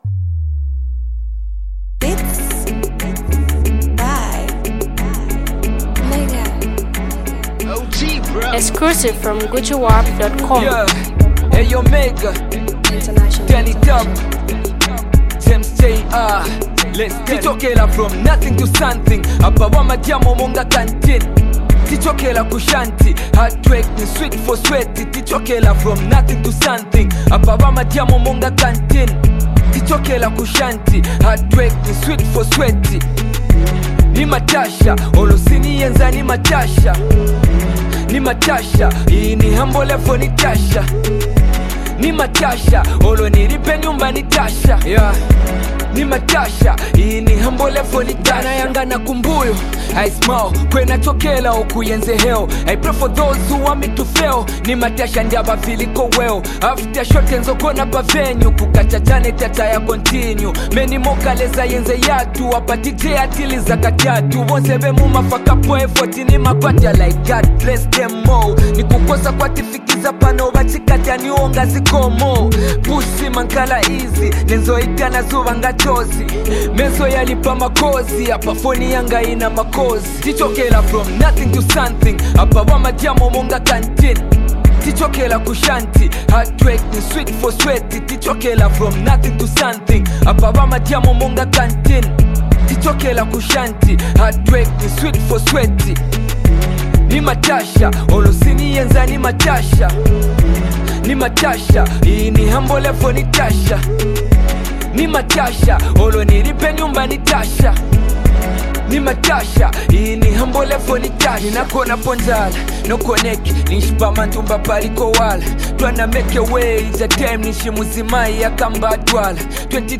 latest Zambian masterpiece
soulful vocals
With its infectious rhythm and heartfelt message